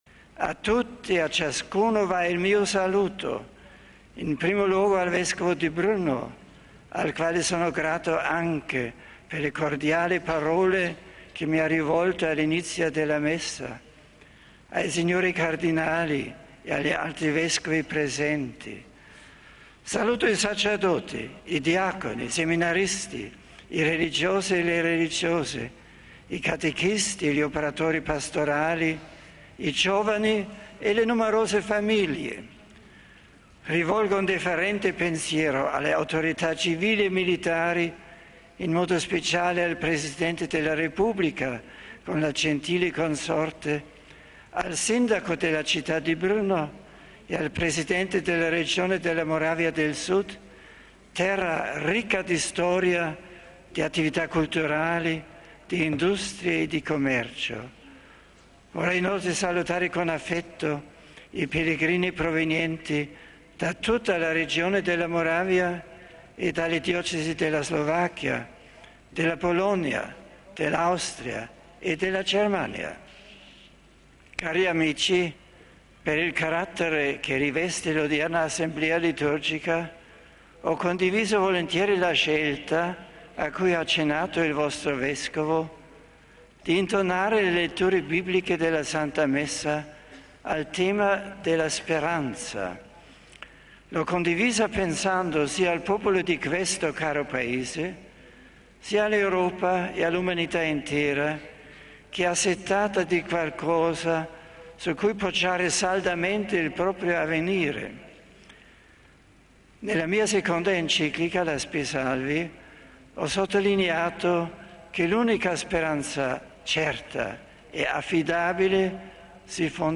Così il Papa nella Messa celebrata davanti a circa 150mila fedeli nei pressi dell'aeroporto di Brno, in Moravia.